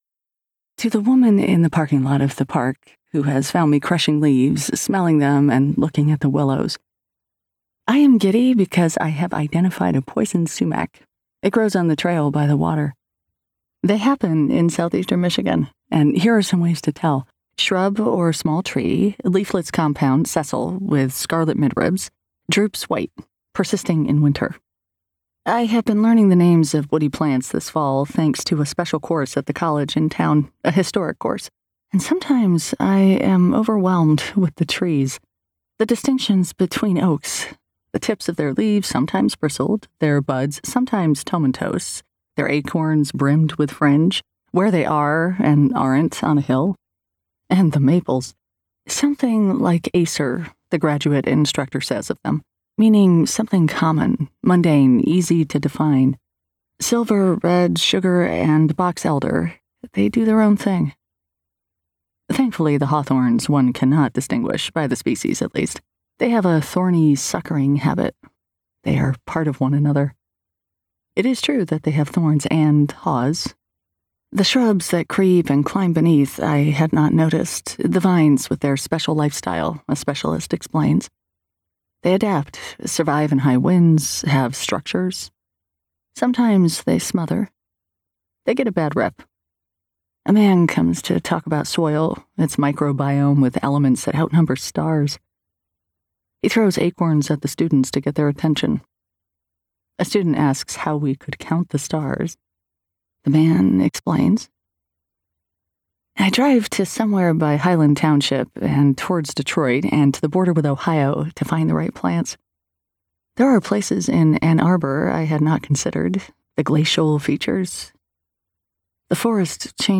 Woman Is a Woman Until She Is a Mother - Vibrance Press Audiobooks - Vibrance Press Audiobooks